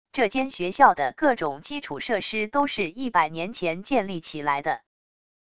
这间学校的各种基础设施都是一百年前建立起来的wav音频生成系统WAV Audio Player